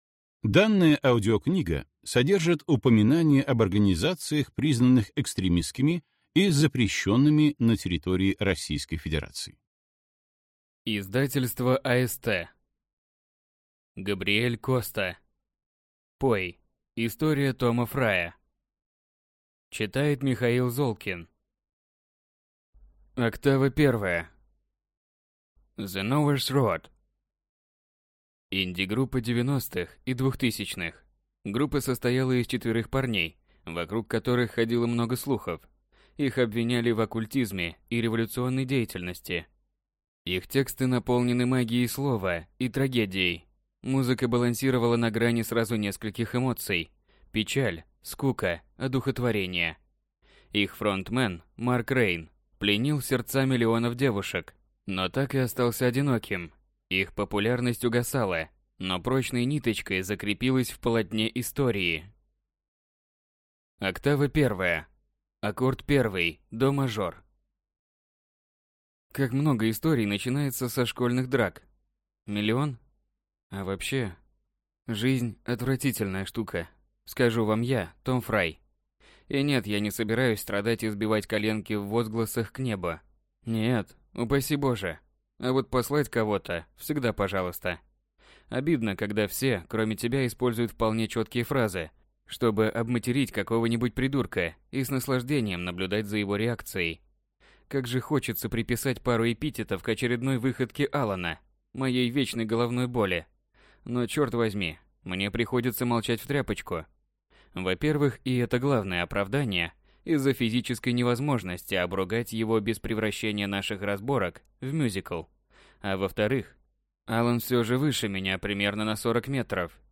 Аудиокнига Пой. История Тома Фрая | Библиотека аудиокниг